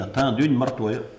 Patois - ambiance
Catégorie Locution